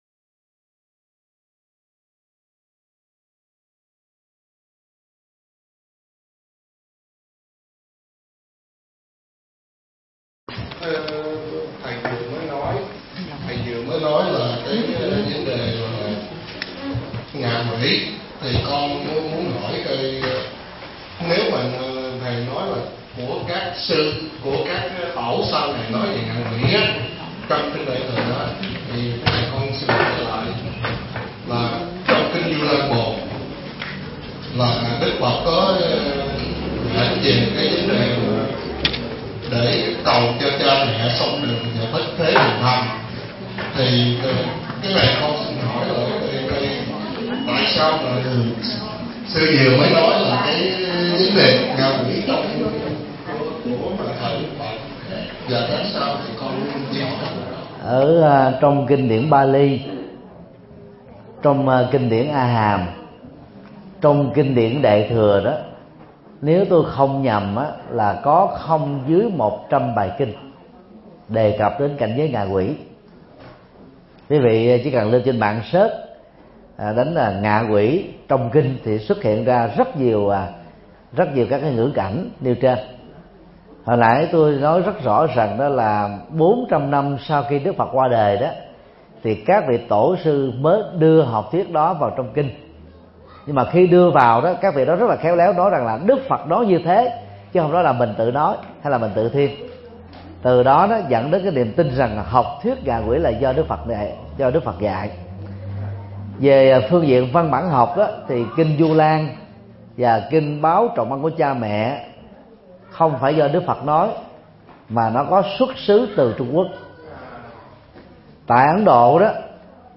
Vấn đáp: Học thuyết ngạ quỷ – Thích Nhật Từ